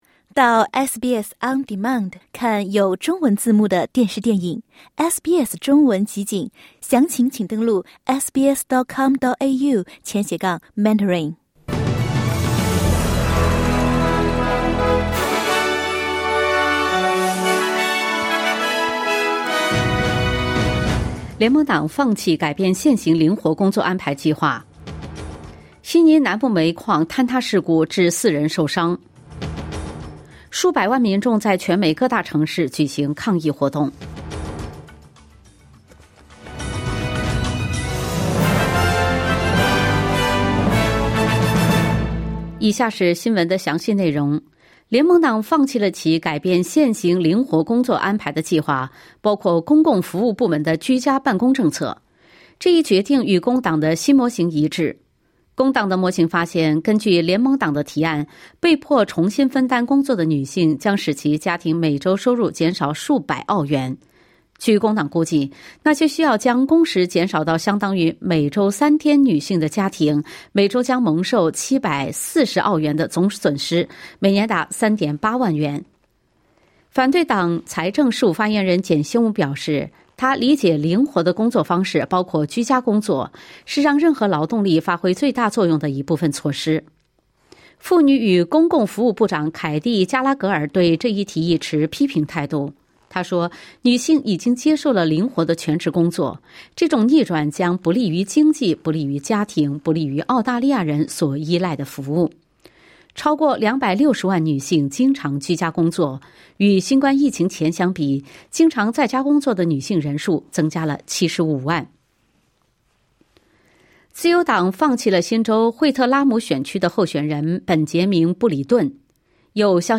SBS早新闻（2025年4月7日）